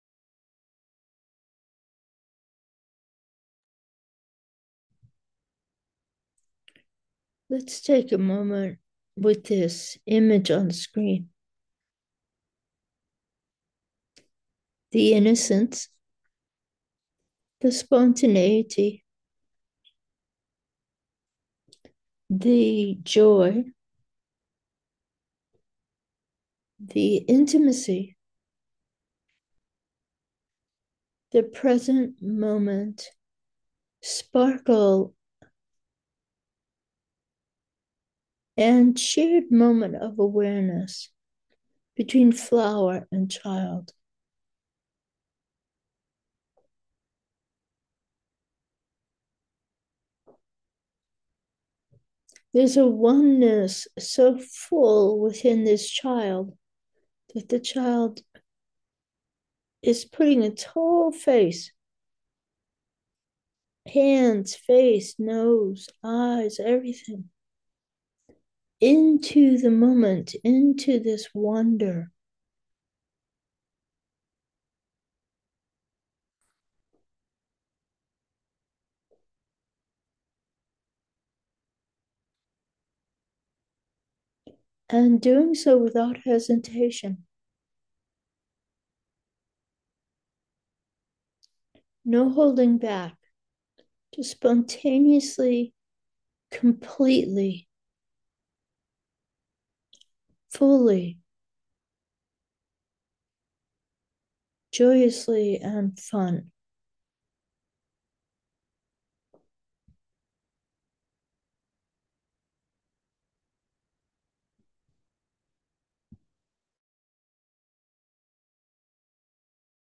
Meditation: a field of gratitude
And, when this is done within a group, such as morning meditation, the field is increased.